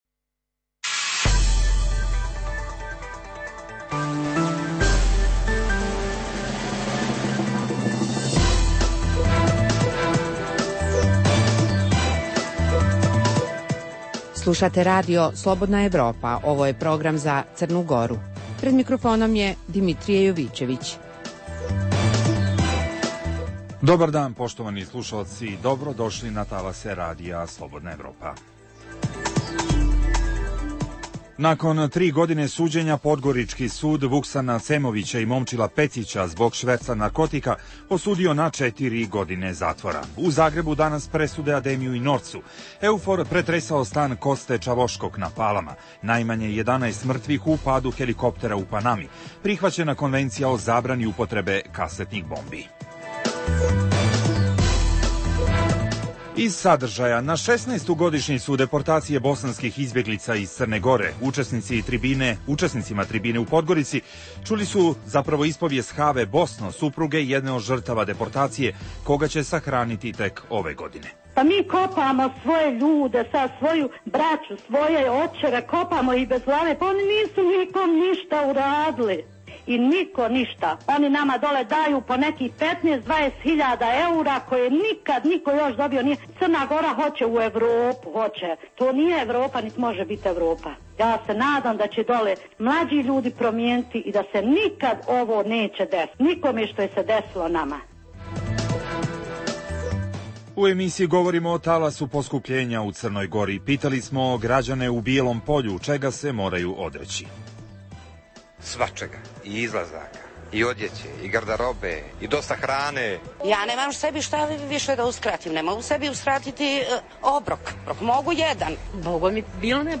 A sa tribine u pomen žrtvama deportacije bh izbjeglica, ponovo je zatraženo je da se država CG konačno suoči sa svojom odgovornošću za taj zločin, da zamoli za oproštaj porodice žrtava, da pravednu reparaciju, ustanovi Dan stida i podigne spomenik žrtvama.